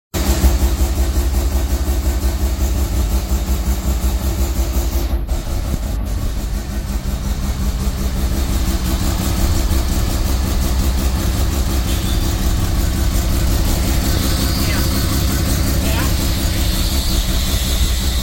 Mazda Rx5 Ready With 13b Sound Effects Free Download
mazda rx5 ready with 13b bridgeport sound